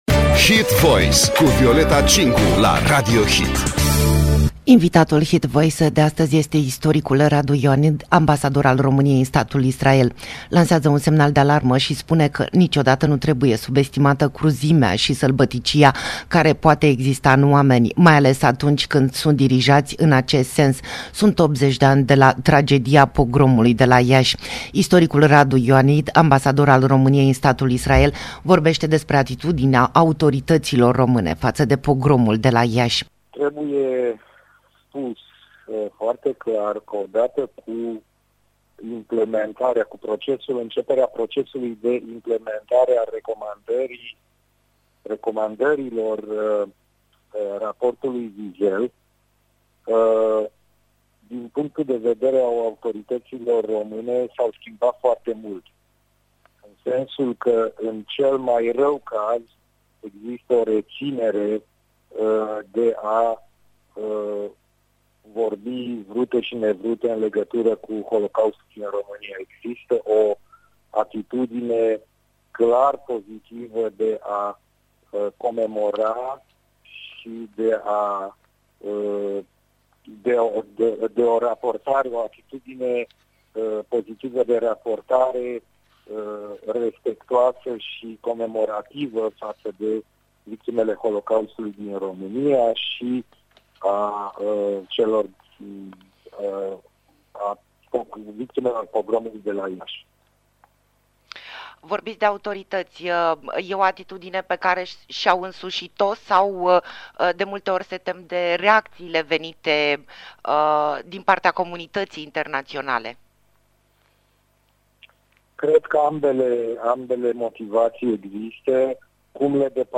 80 de ani de la Pogromul de la Iași. Interviu cu istoricul Radu Ioanid, ambasador al României în statul Israel - Radio Hit